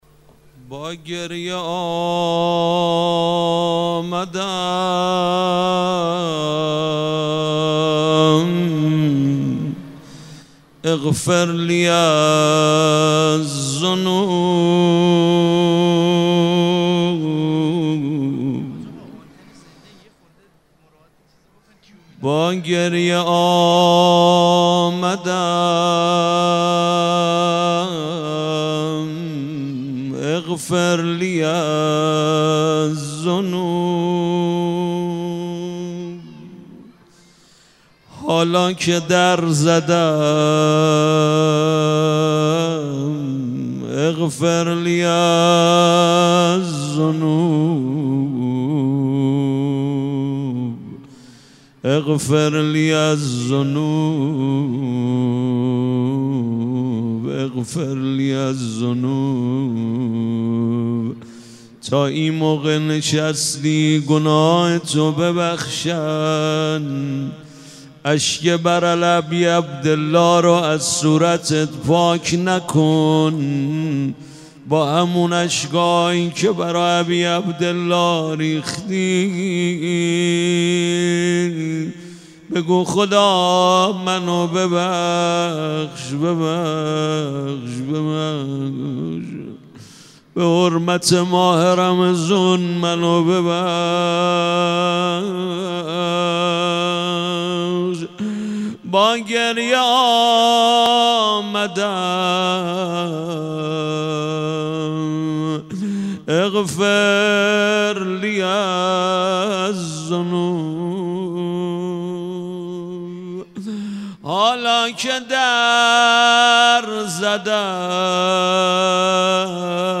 دعای کمیل
حرم حضرت فاطمه معصومه (س) با ترافیک رایگان